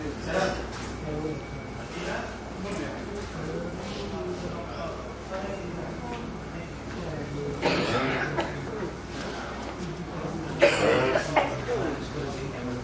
描述：稍微东部或可能是阿拉伯语。循环合成短语。制作FL工作室。